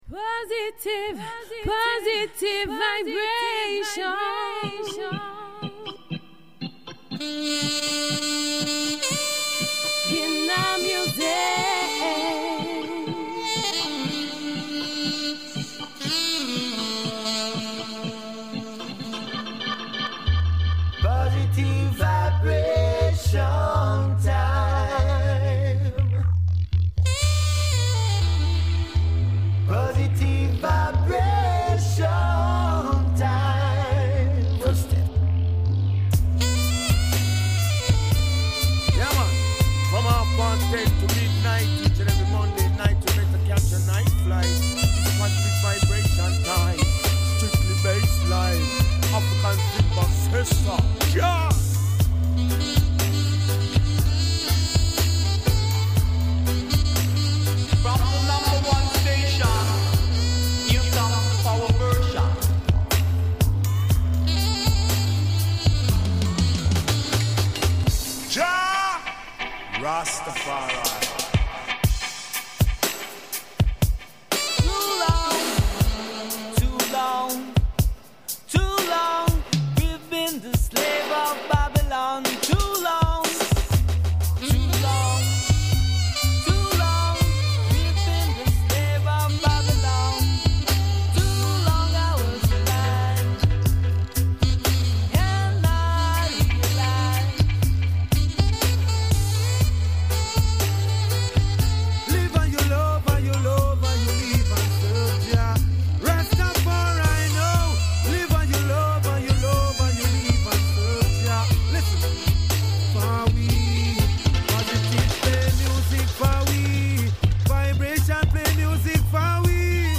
Roots Rock Reggae
inna Dubplate styleeeeeeeeeeee